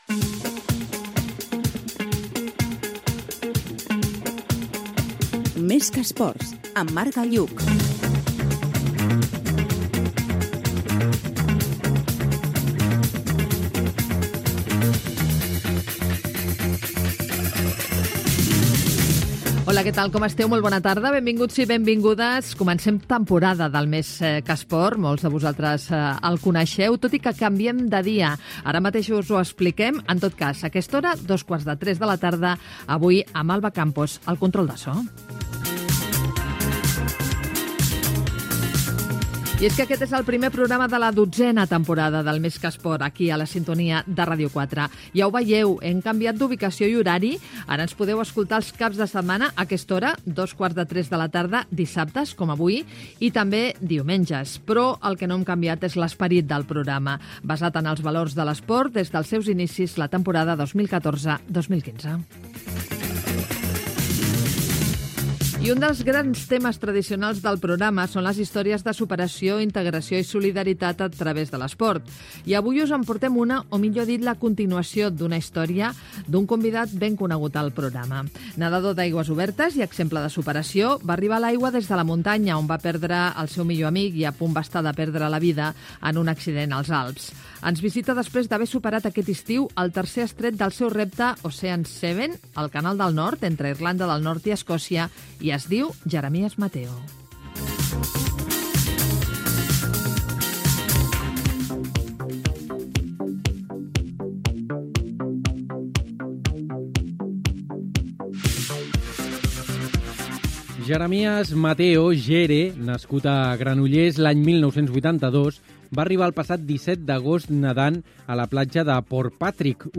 Careta del programa, salutació, avís del canvi d'horari i de dia d'emissió.
Esportiu